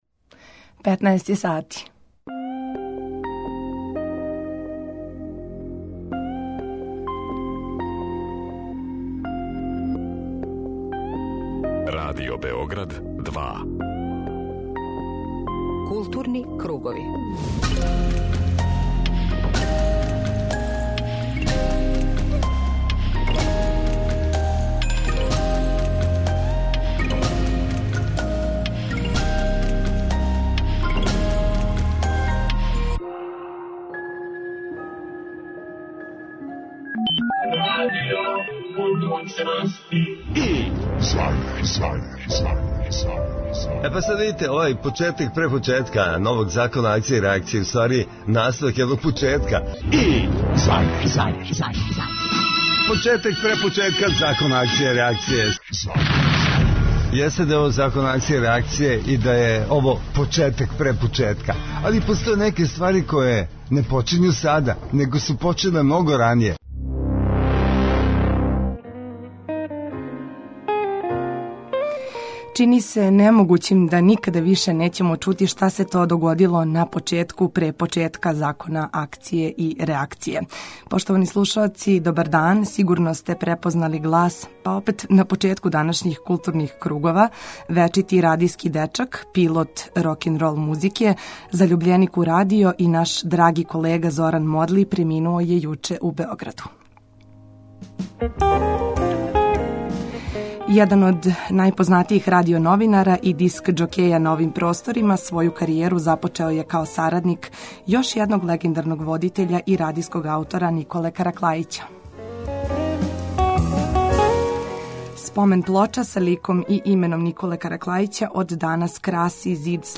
У емисији Културни кругови данас вам преносимо део атмосфере из зграде нашег радија - чућете како је протекла свечаност постављања плоче Николе Караклајића на Зид славних Другог програма Радио Београда.